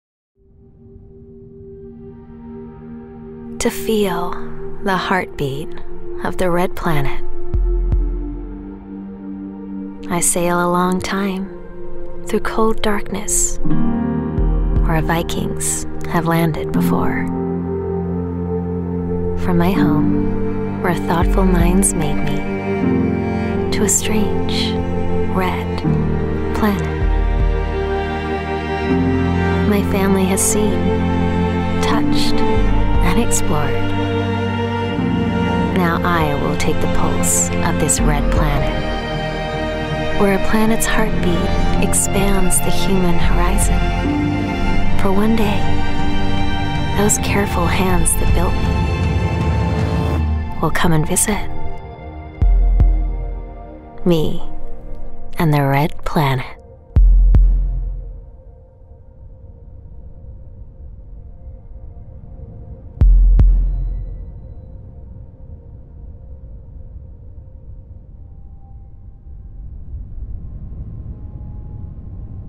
OFF-Kommentar Englisch (US)